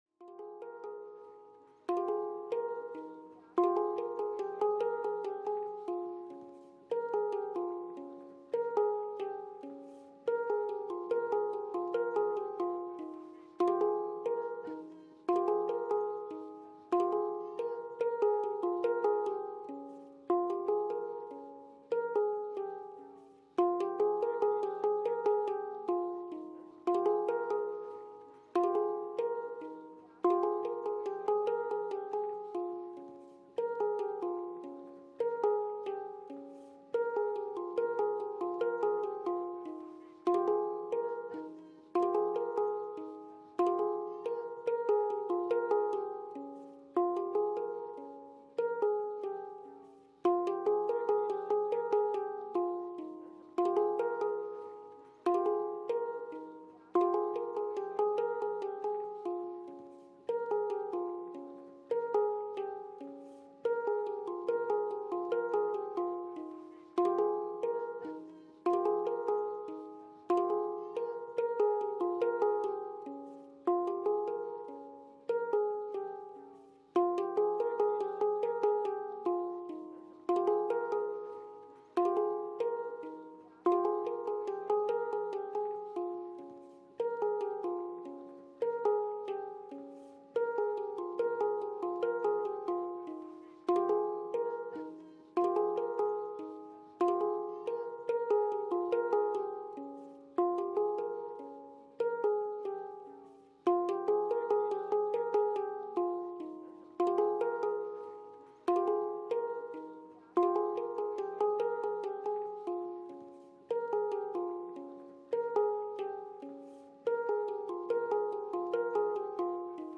Handpan-Loop.mp3